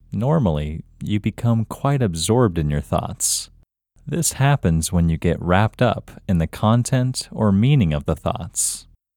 LOCATE OUT English Male 7